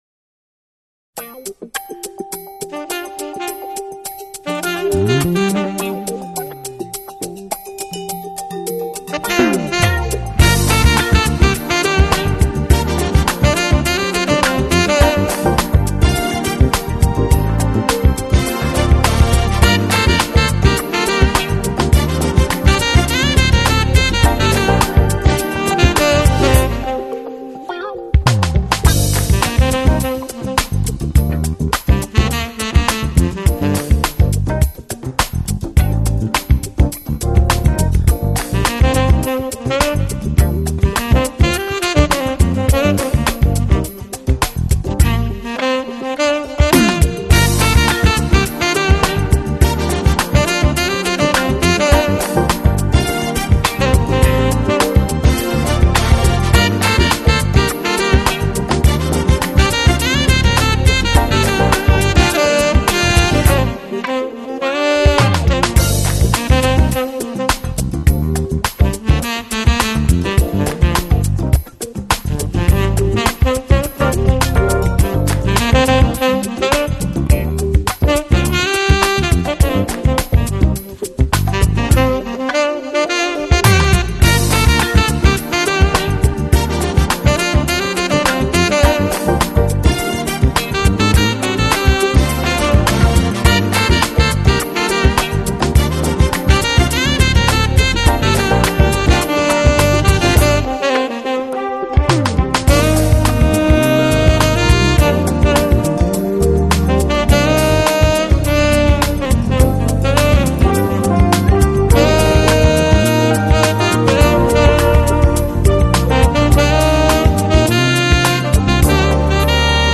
风格：Smooth Jazz, R&B
一切都是显得的 那么的平滑，略微上扬的节奏是时下非常流行的元素